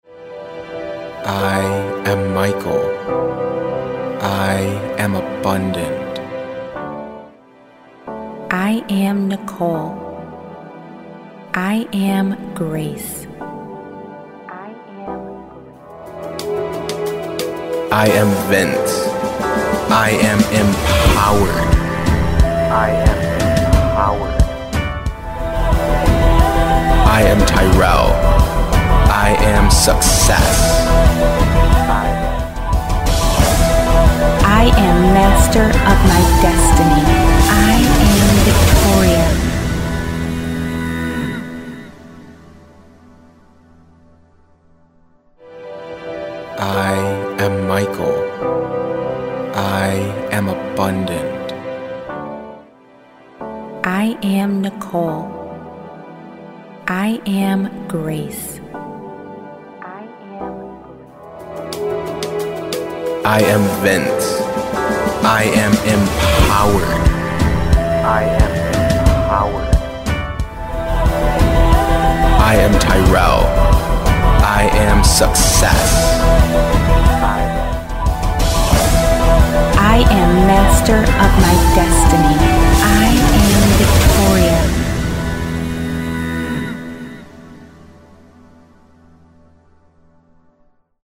Professionally recorded audio affirmations tailored for everyone's name!
Words of Affirmation Recorded Professionally For You
On the other hand, our professionally mixed and mastered recordings are not only full of inspiring positive affirmations but more importantly, we speak them for you in the first person, mentioning your name various times throughout the entire recording.
Samples-Of-Multiple-Names-LOOP.mp3